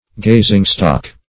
Search Result for " gazingstock" : The Collaborative International Dictionary of English v.0.48: Gazingstock \Gaz"ing*stock`\, n. A person or thing gazed at with scorn or abhorrence; an object of curiosity or contempt.